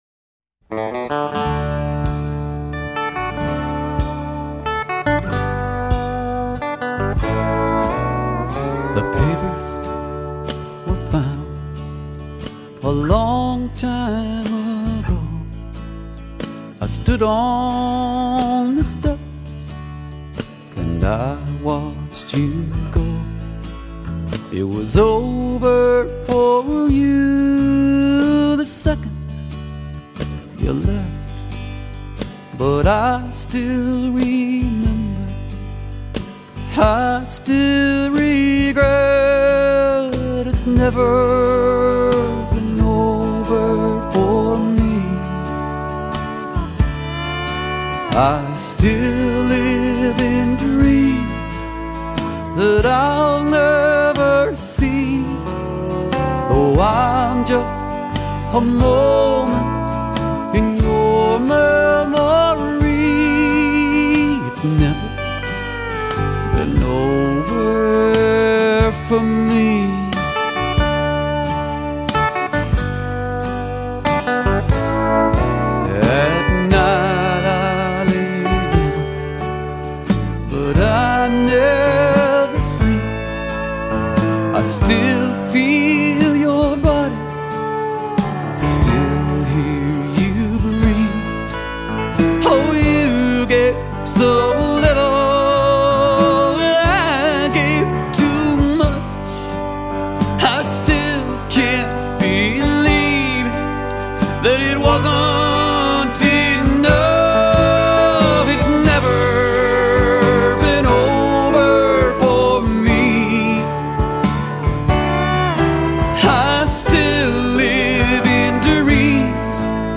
vocals, guitar
Bass
Drums
Pedal Steel, Telecaster